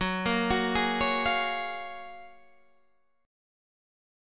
GbM9 chord